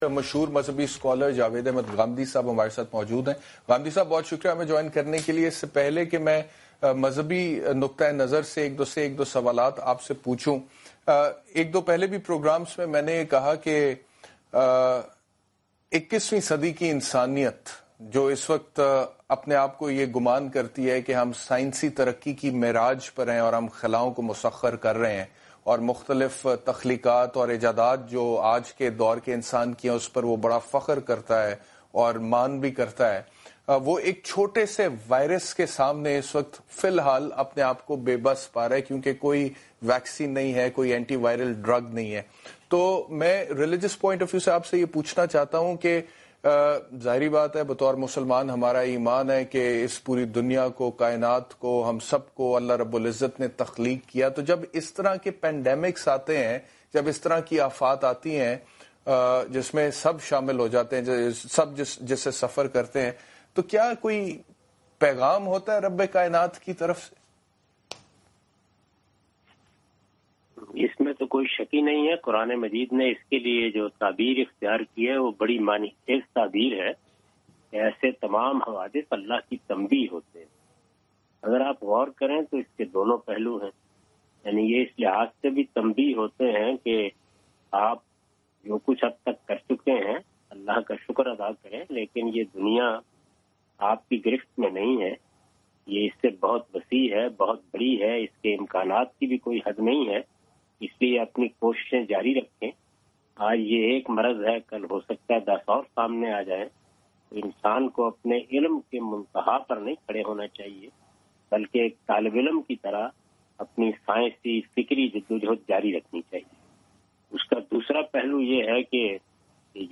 Category: TV Programs / Samaa Tv / Questions_Answers /
Javed Ahmad Ghamidi expresses his views on SAMAA NEWS about "How to respond to Coronavirus Pandemic".